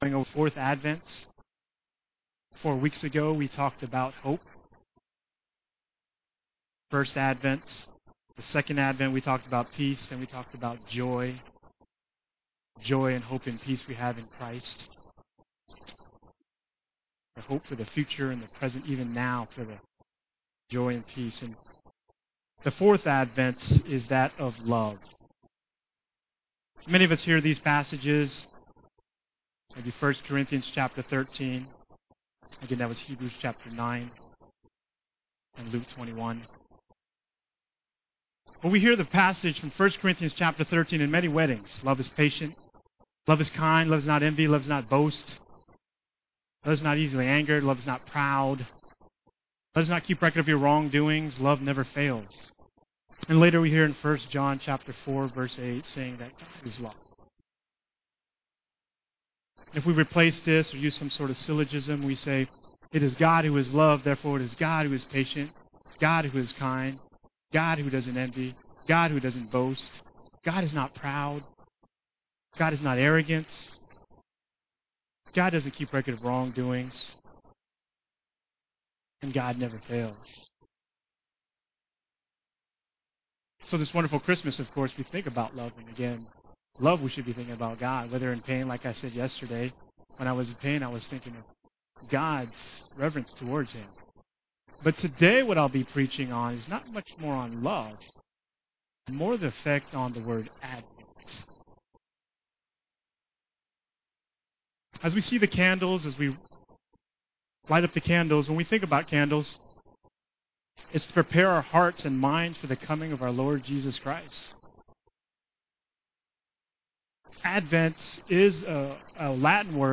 Sermons Archive - Page 16 of 19 - FPBC